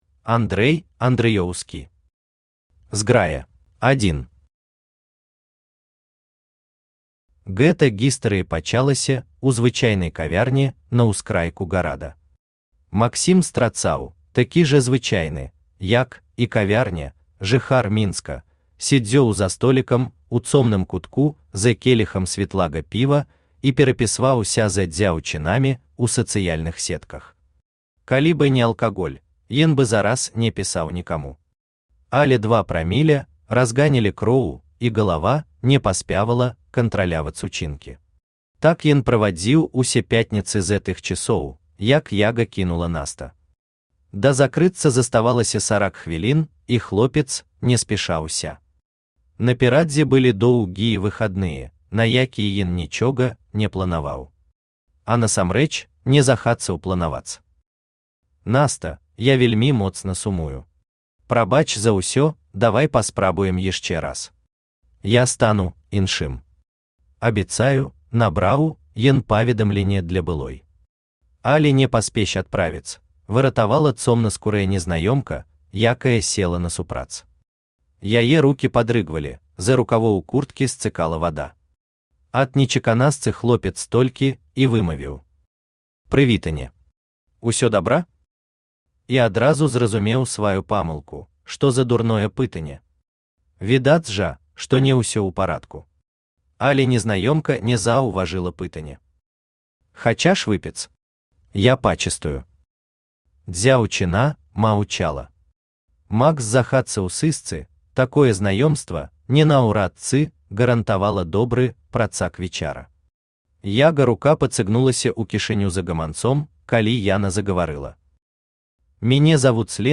Аудиокнига Зграя | Библиотека аудиокниг
Aудиокнига Зграя Автор Андрэй Андрыеўскі Читает аудиокнигу Авточтец ЛитРес.